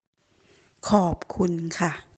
Pour vous entraîner voici la bonne prononciation:
audio-merci-en-thai-femme.mp3